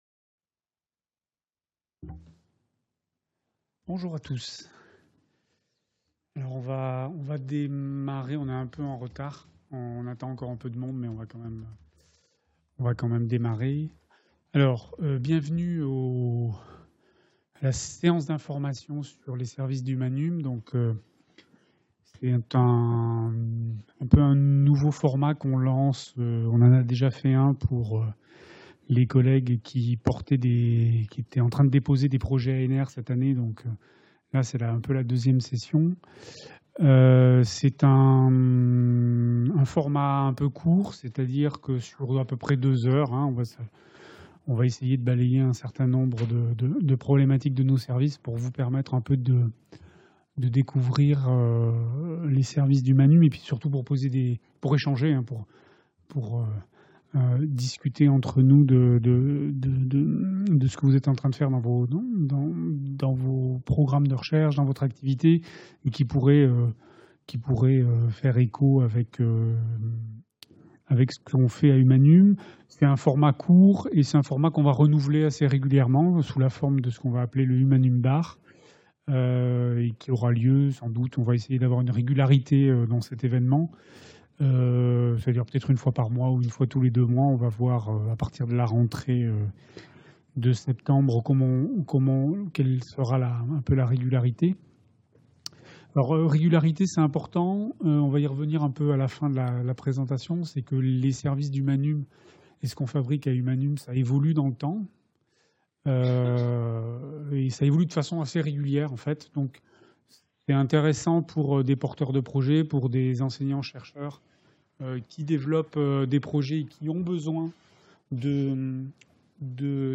L’équipe présentera les possibilités offertes par les services numériques d’Huma-Num et répondra aux questions des porteuses et porteurs de projets.
Cette réunion est organisée dans le forum de la Bibliothèque-Laboratoire de la Fondation Maison des sciences de l’homme (FMSH, 54, Bd Raspail 75006 Paris, 1er étage). https